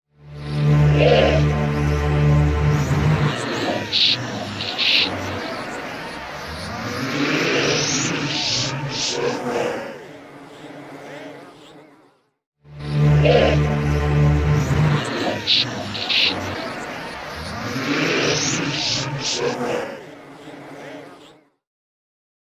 pop rock
L'inizio di brano � pieno zeppo di voci filtrate o distorte.